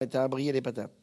Mémoires et Patrimoines vivants - RaddO est une base de données d'archives iconographiques et sonores.
Enquête Arexcpo en Vendée
Catégorie Locution